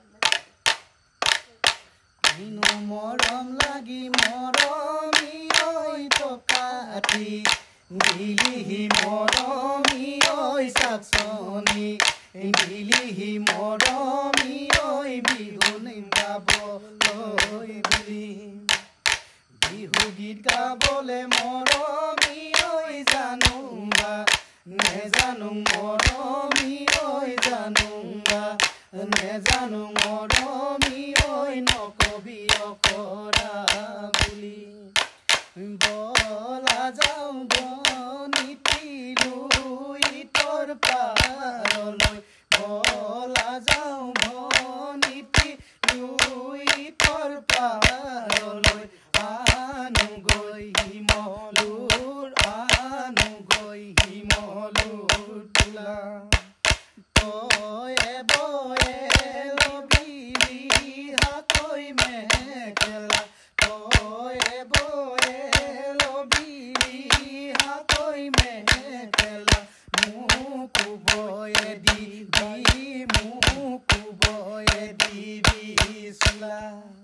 Playing with folk song